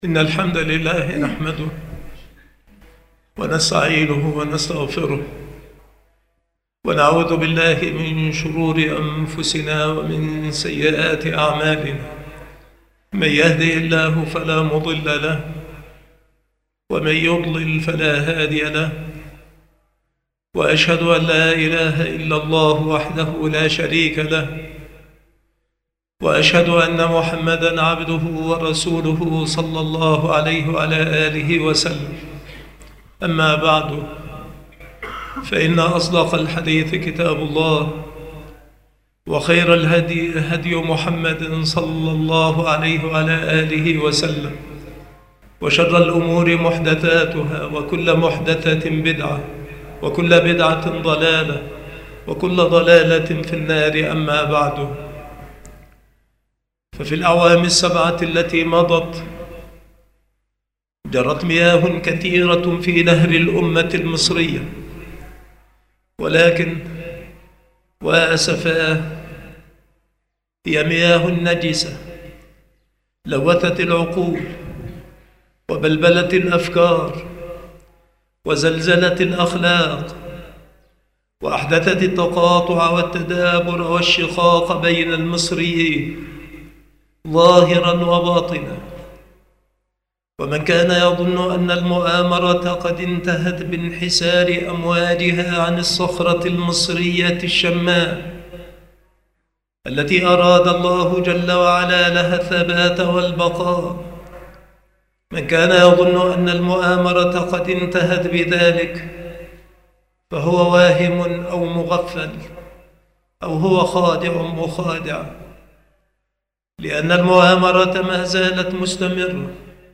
المحاضرة
مكان إلقاء هذه المحاضرة بالمسجد الشرقي - سبك الأحد - أشمون - محافظة المنوفية - مصر